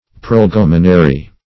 Search Result for " prolegomenary" : The Collaborative International Dictionary of English v.0.48: Prolegomenary \Prol`e*gom"e*na*ry\, a. Of the nature of a prolegomenon; preliminary; introductory; prefatory.
prolegomenary.mp3